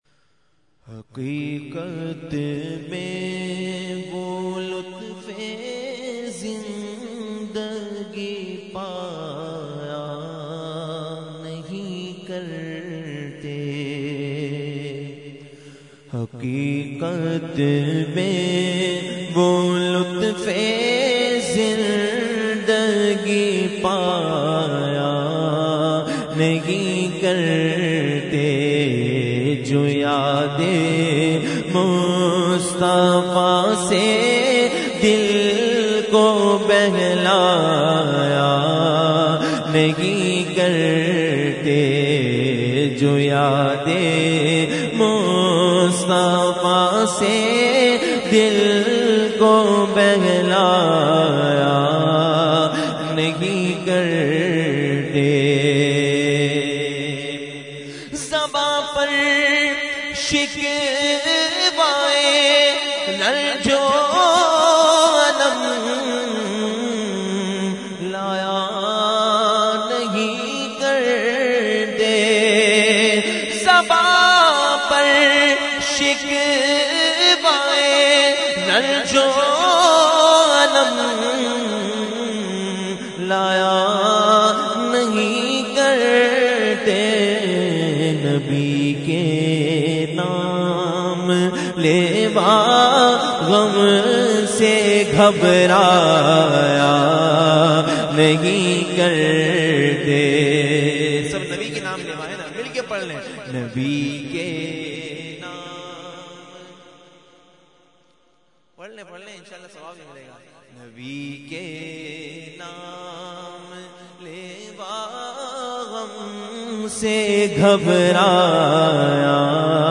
Category : Naat | Language : UrduEvent : Mehfil 11veen Nazimabad 23 March 2012